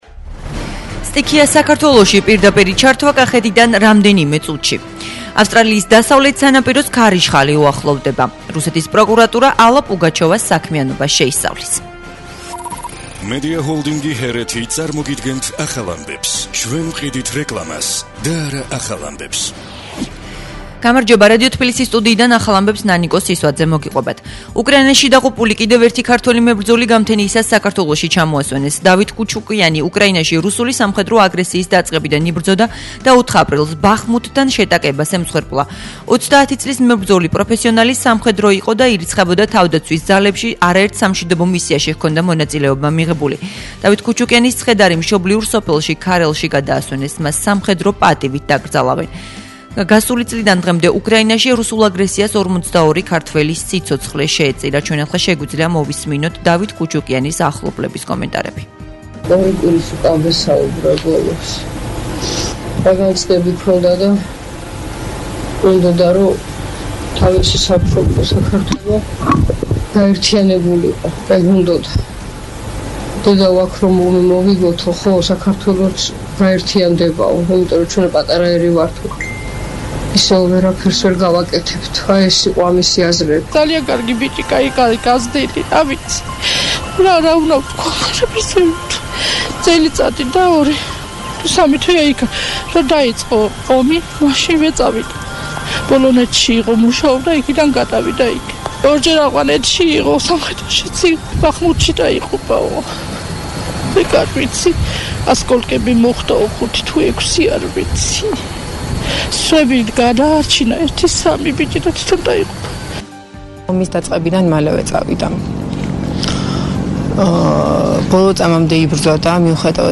სტიქია საქართველოში - პირდაპირი ჩართვა კახეთიდან ავსტრალიის დასავლეთ სანაპიროს ქარიშხალი უახლოვდება
ახალი ამბები 12:00 საათზე